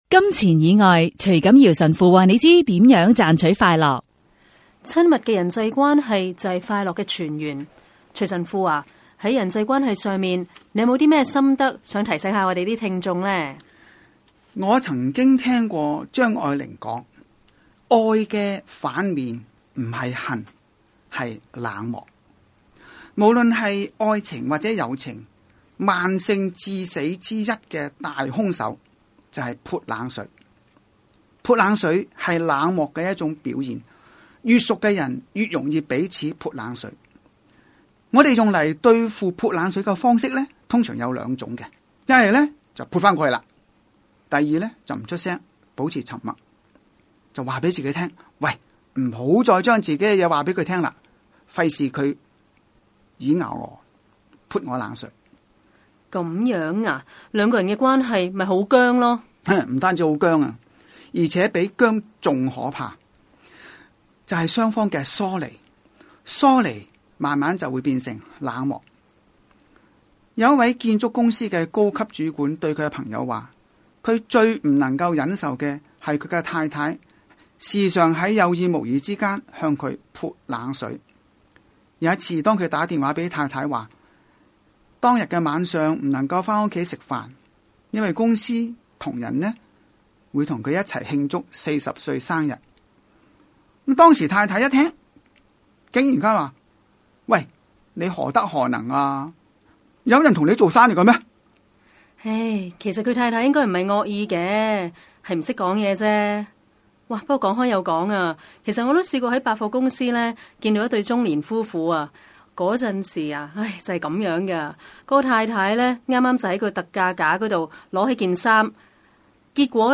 自2007年底，我們在新城財經台推出「金錢以外」電台廣播節目，邀請不同講者及團體每晚以五分鐘和我們分享金錢以外能令心靈快樂、生命富足的生活智慧，讓聽眾感悟天主的美善和睿智。